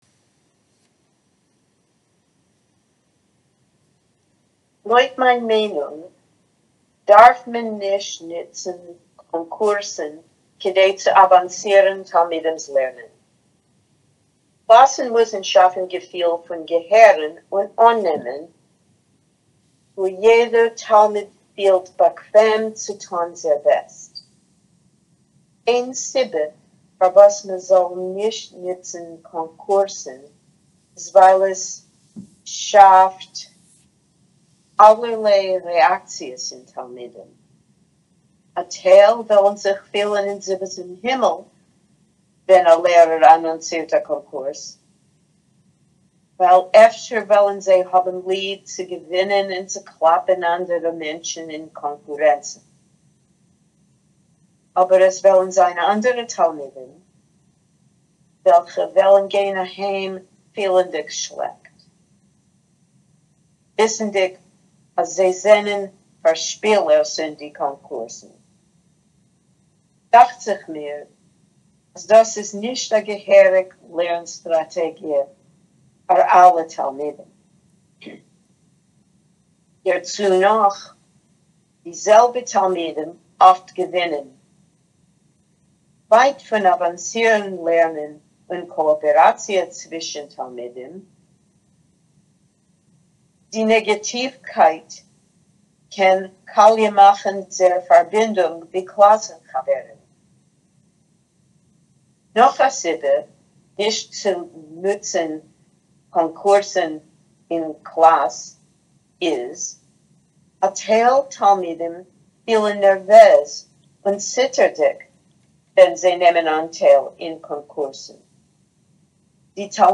[Note: In the transcript below, two dots indicate that the speaker paused.